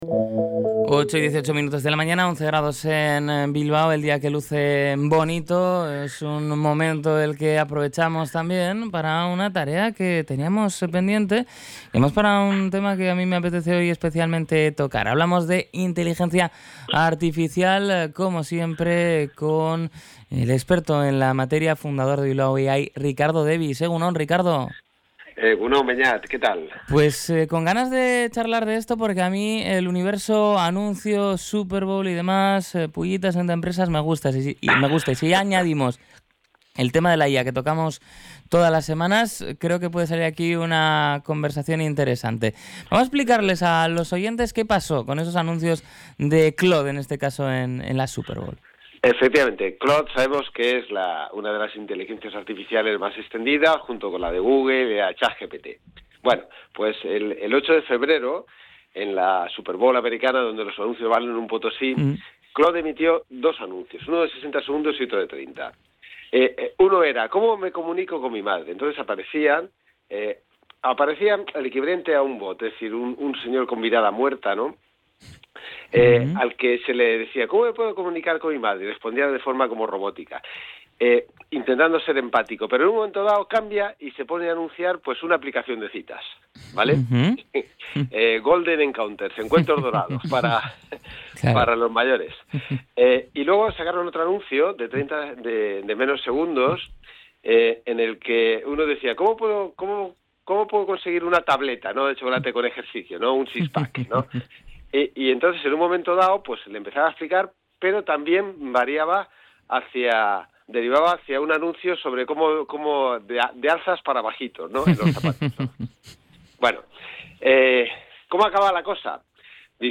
Podcast Tecnología